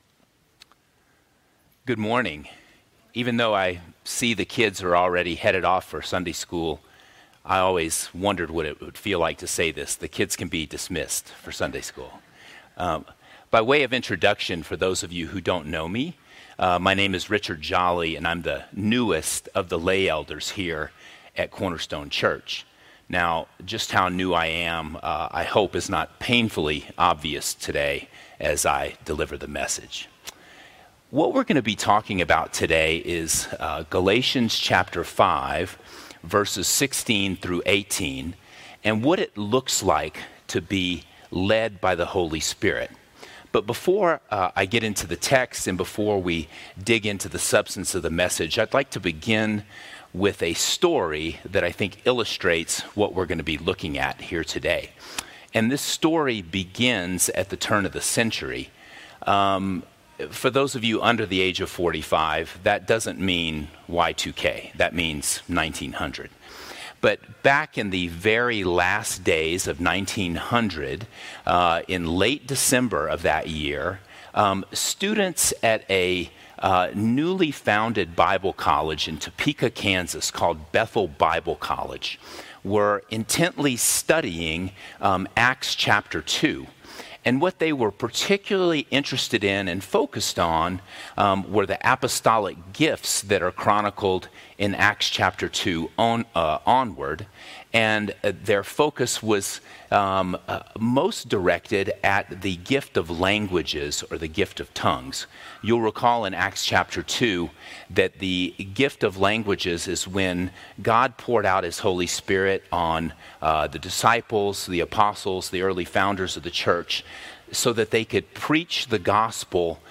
[sermon] Galatians 5:16-18 What It Looks Like To Be Led By The Spirit | Cornerstone Church - Jackson Hole